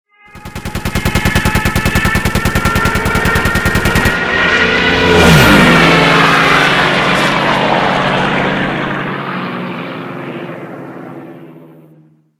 Звуки пролёта
Военный самолет ведет огонь